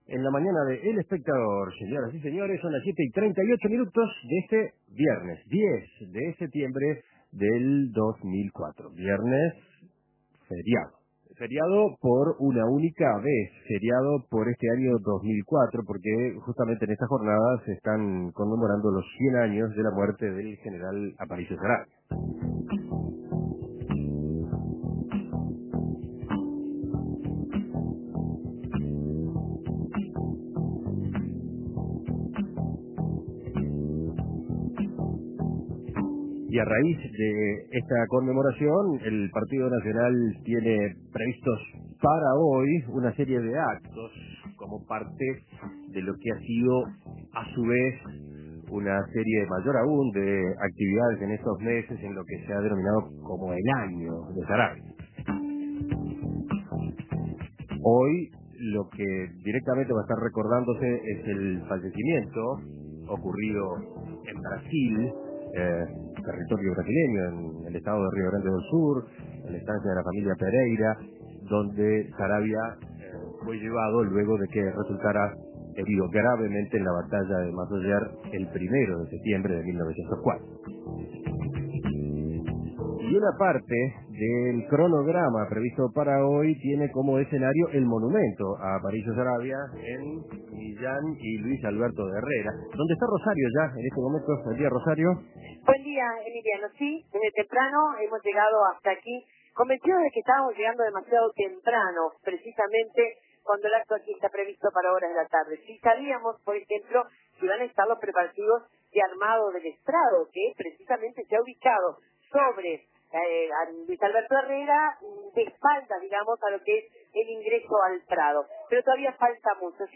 La vigilia junto al monumento a Aparicio Saravia.